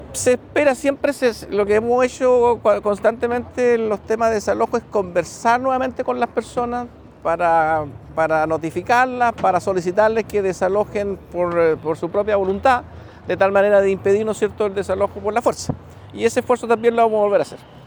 Eso sí, a pesar de la solicitud de los vecinos, el delegado Toro dijo que habrá que realizar algunos trámites de nuevo, por lo que el desalojo y demolición de la estructura tendrá que seguir esperando.